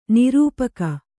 ♪ nirūpaka